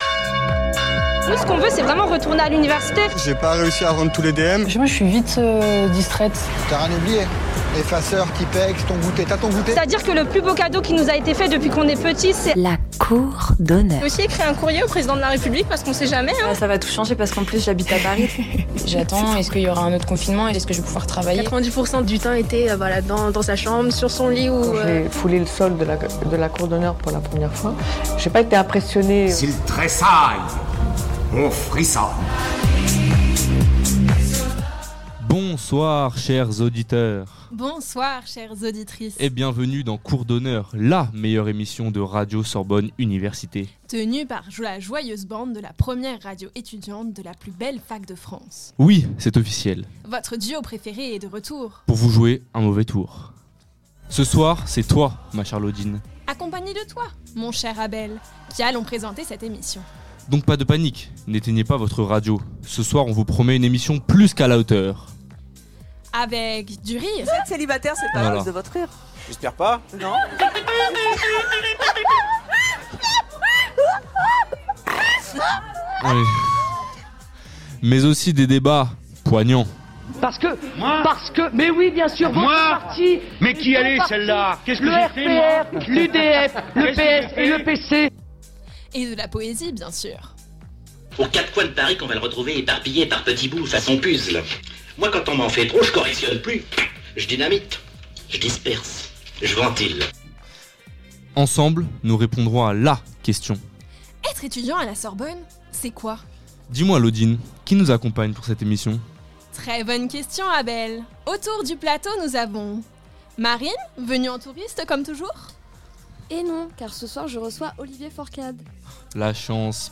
La vie étudiante à la Sorbonne sous toutes ses facettes. Anecdotes sur la plus vieille fac de France, interview d'enseignants, reportages sur le quotidien des étudiants, carte postale sonore des lieux emblématique de l'université.